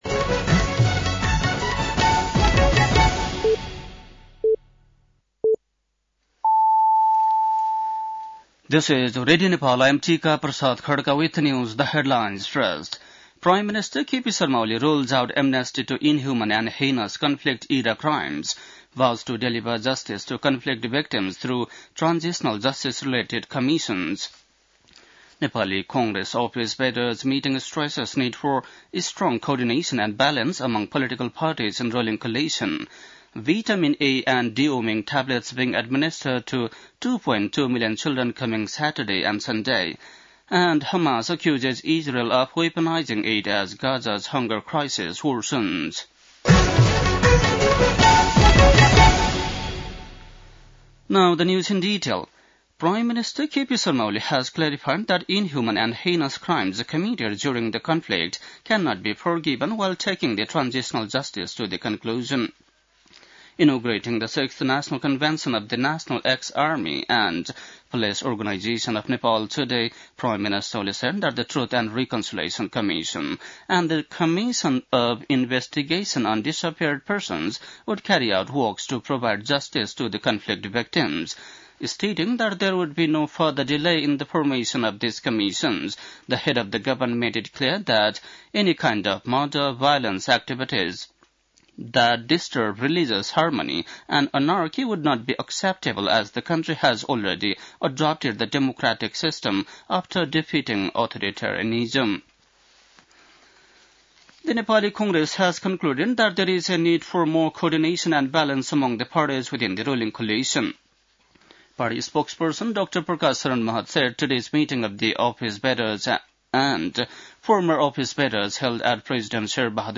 बेलुकी ८ बजेको अङ्ग्रेजी समाचार : ४ वैशाख , २०८२
8-pm-english-news-1-04.mp3